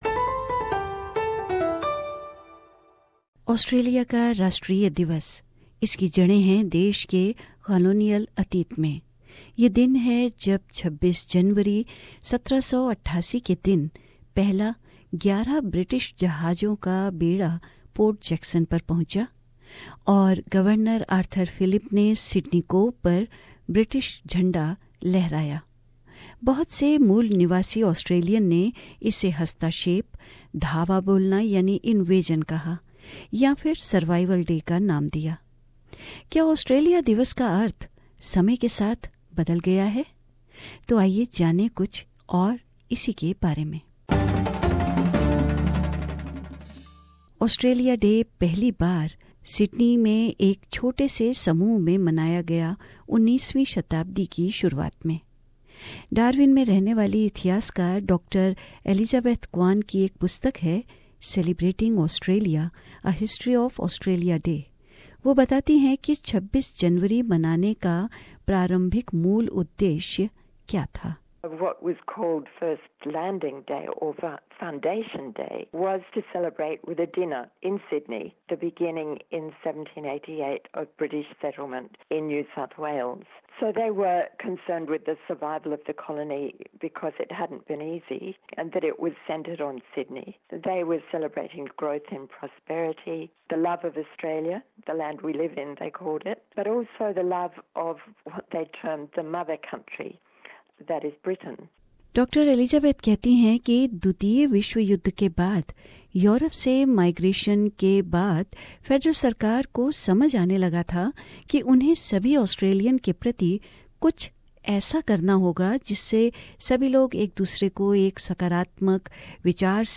Settlement Feature: What is Australia Day